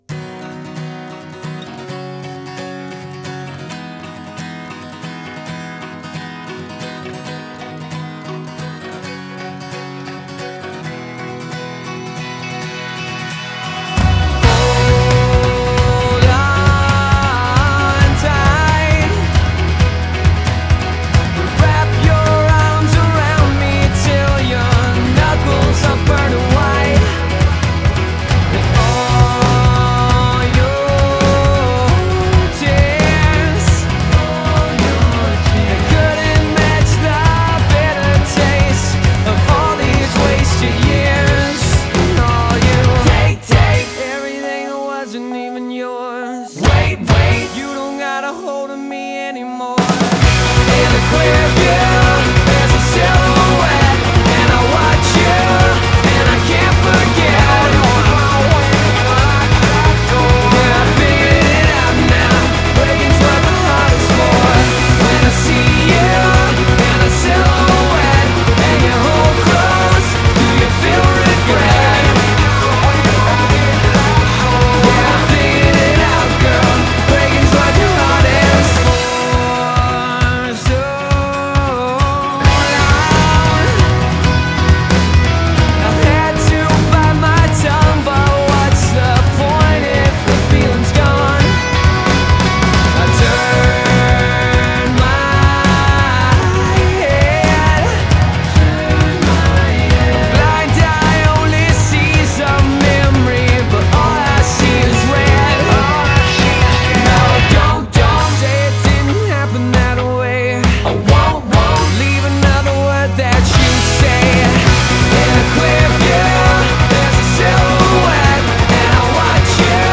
Тема: поп-рок